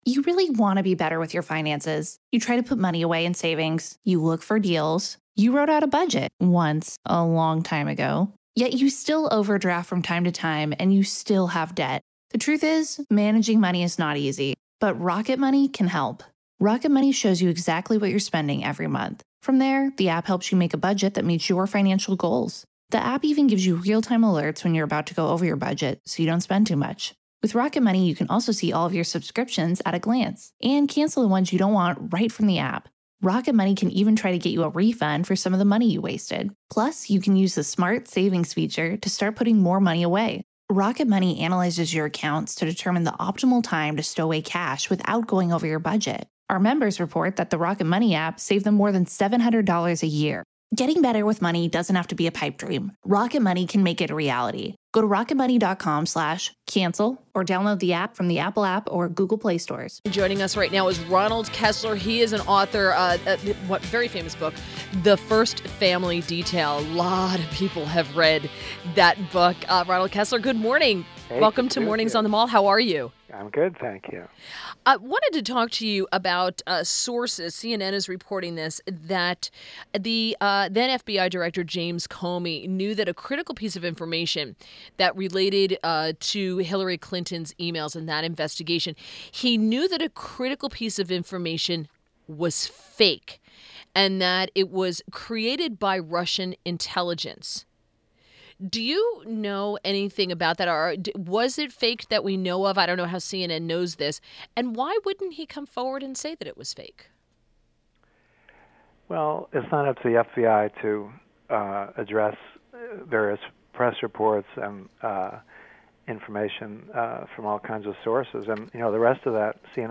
WMAL Interview - RONALD KESSLER 05.31.17